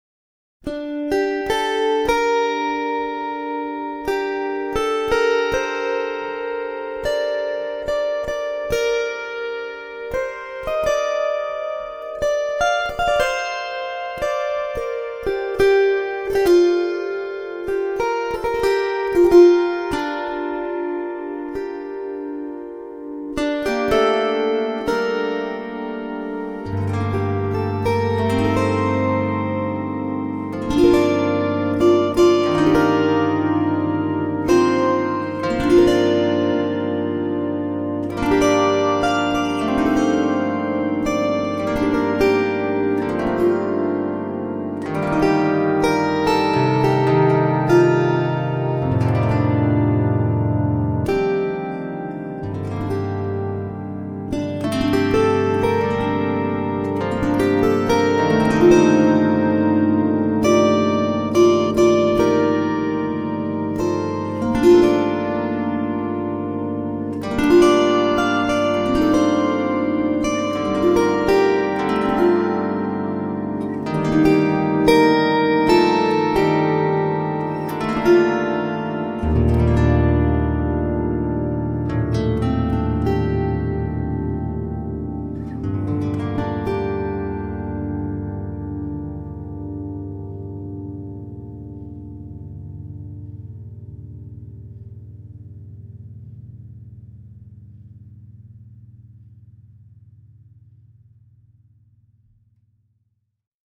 MÚSICAS MEDIOEVALI Y CELTA